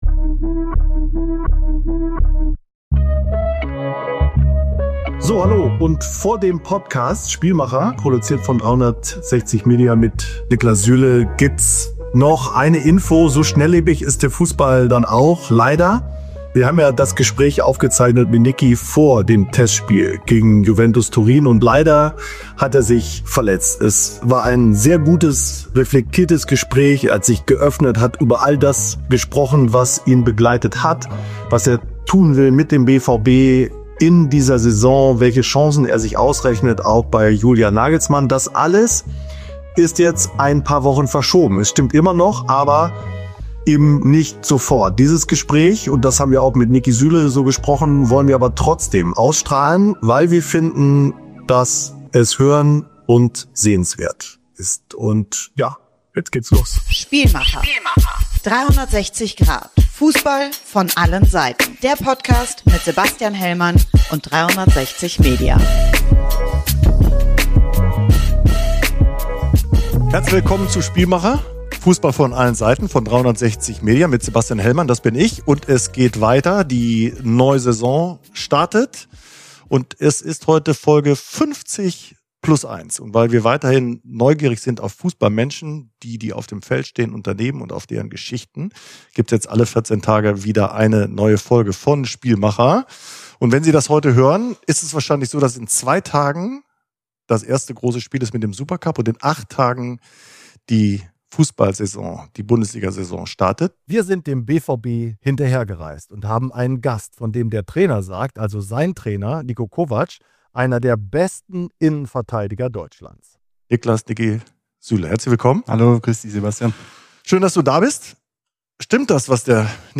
Ein eindrucksvolles Gespräch, das wenige Tage vor seiner Verletzung im Test gegen Juventus Turin aufgenommen wurde.